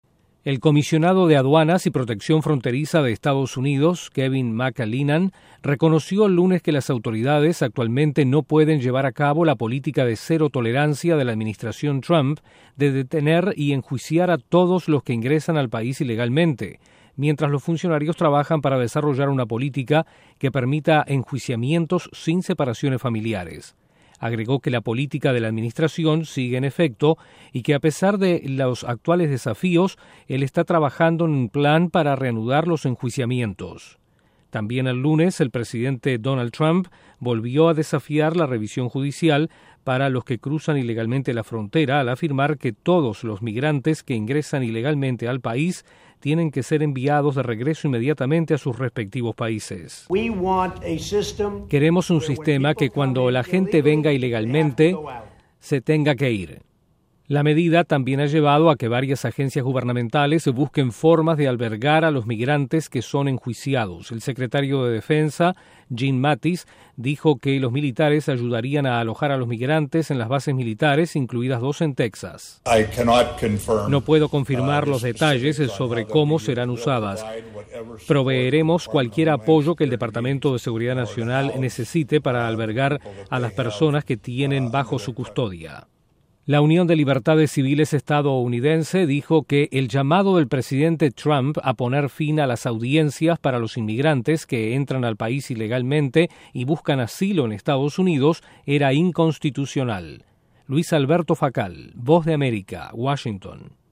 Un alto funcionario de EE.UU. dice que las autoridades no pueden llevar a cabo la política de “cero tolerancia” a inmigrantes que ingresan ilegalmente, pero están trabajando para implementar el plan. Desde la Voz de América en Washington informa